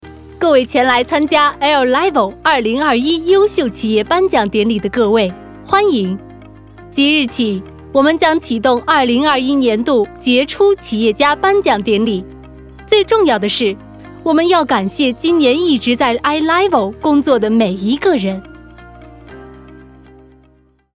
Voice actor sample
밝음/희망